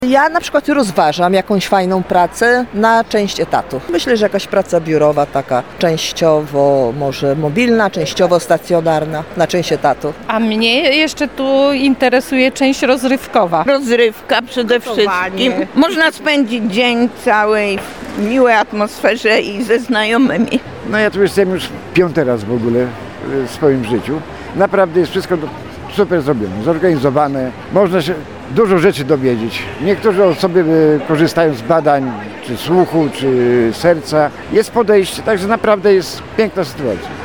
Wydarzenie odbywające się na AmberExpo odwiedziły tłumy seniorów. Rozmawiał z nimi nasz reporter.